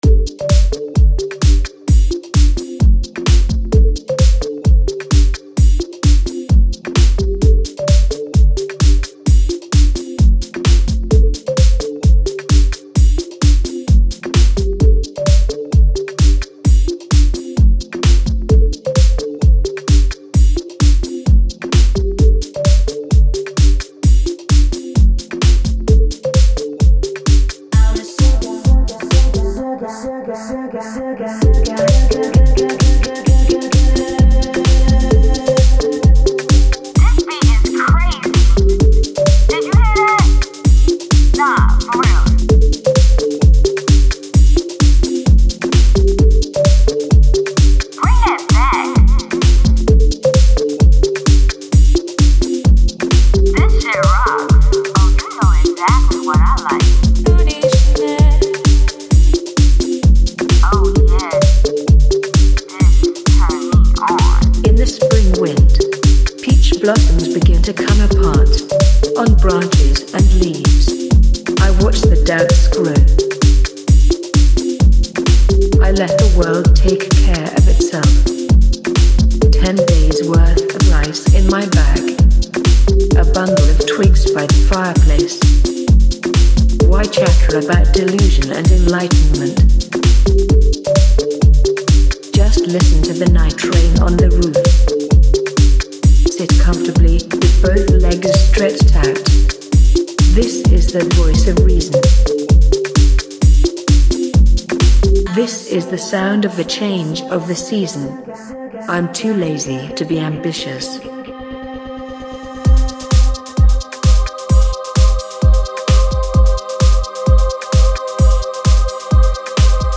hab wieder (seit jahren) bei der hitze aus langeweile samples im fl studio zusammengeschubst und das ding "too lazy to be ambitious" genannt. gemixt ist garnicht, bissel eq und limiter auf die summe und fertig. was denkt ihr drüber? soll ich doch noch ein intro machen? 57104